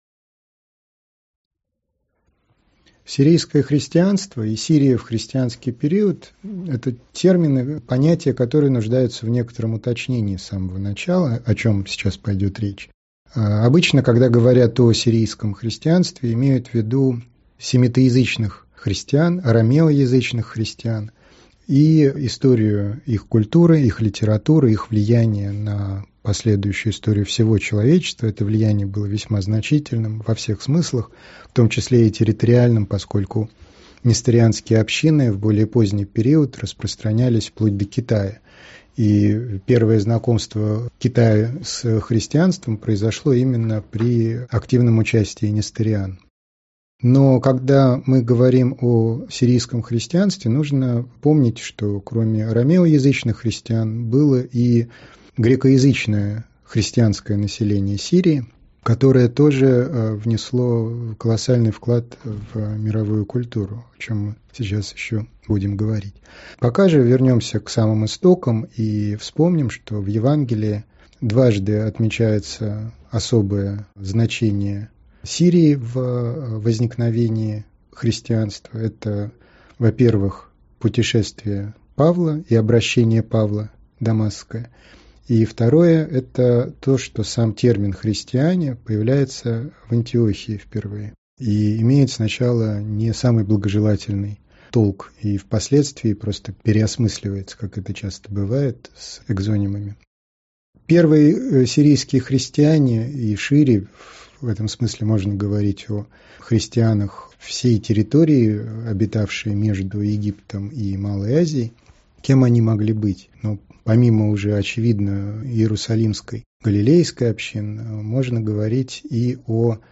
Аудиокнига Христианская Сирия | Библиотека аудиокниг